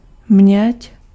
Ääntäminen
Etsitylle sanalle löytyi useampi kirjoitusasu: měď med Ääntäminen Tuntematon aksentti: IPA: /mɲɛc/ Haettu sana löytyi näillä lähdekielillä: tšekki Käännös 1. rame {m} Suku: f .